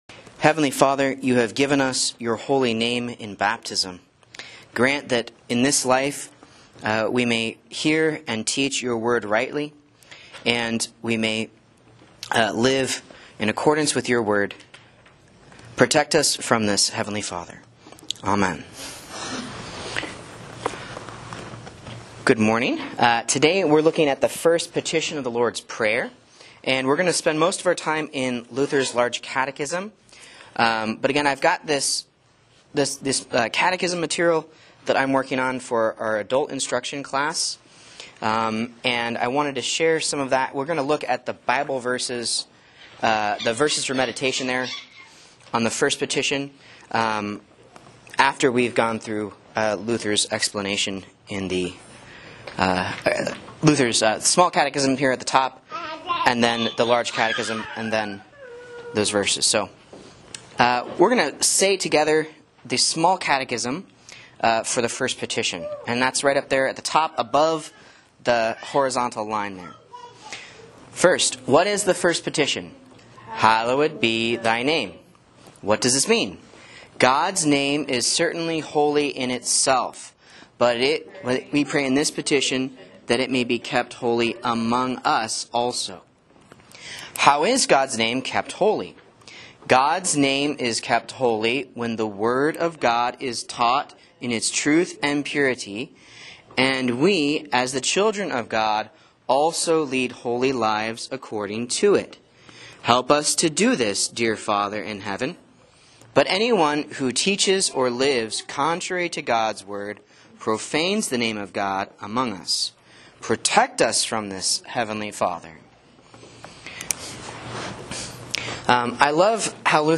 Sermon and Bible Class Audio from Faith Lutheran Church, Rogue River, OR